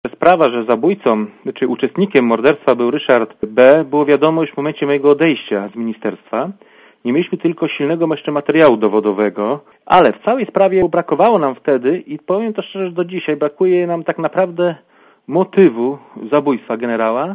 Mówi Marek Biernacki (135Kb)